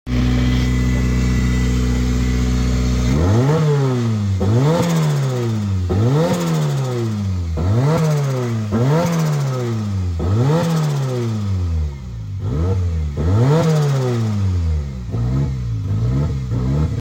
Reflujo 2zz turbo sound effects free download